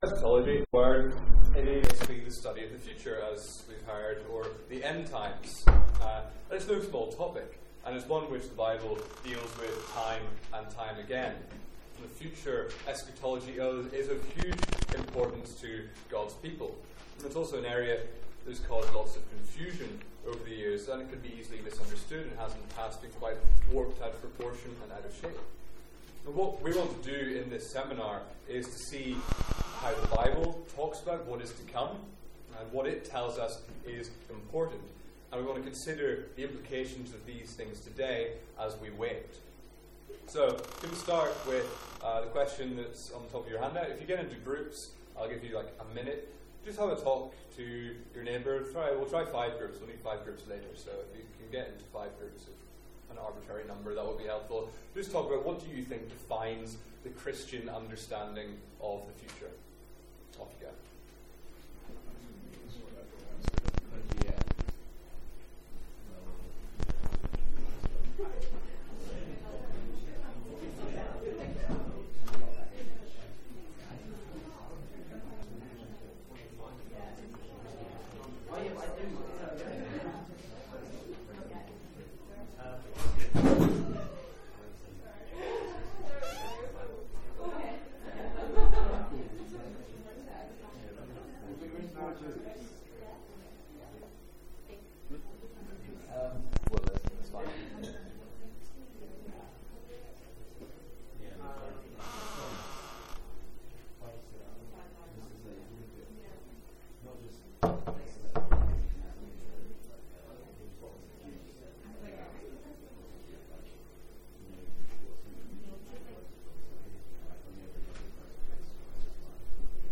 Sermons | St Andrews Free Church
From our student Mid-Year Conference.